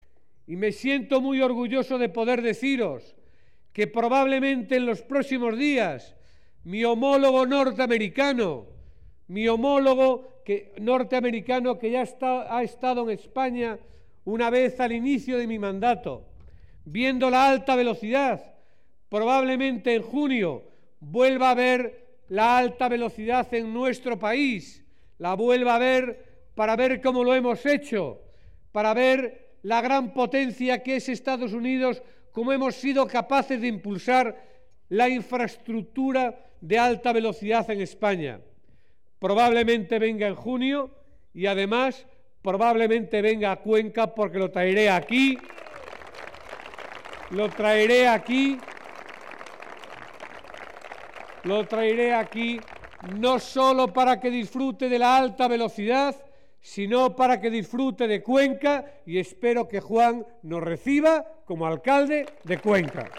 Blanco ha hecho este anuncio durante el acto público que ha ofrecido en la capital conquense, en el que ha opinado además que De Cospedal debe aceptar un debate con José María Barreda porque “es un derecho democrático y los ciudadanos tienen derecho a que se explique, a que diga qué va a hacer”.